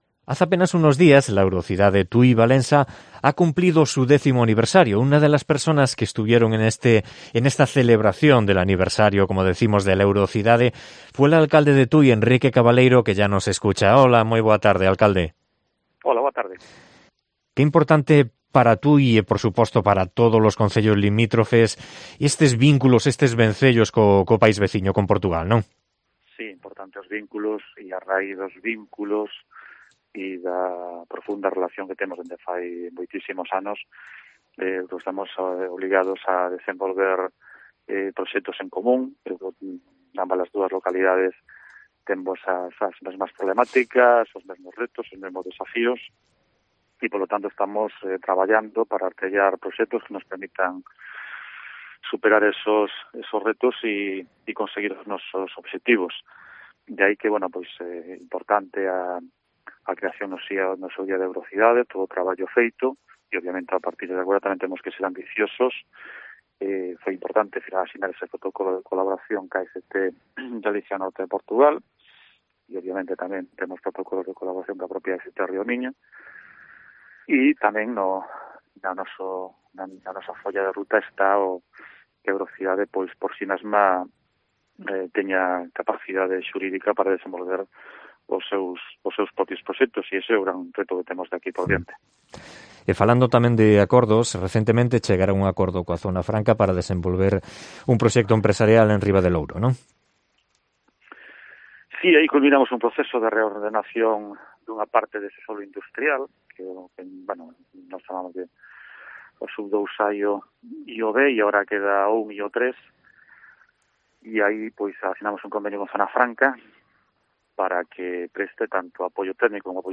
Entrevista a Enrique Cabaleiro, alcalde de Tui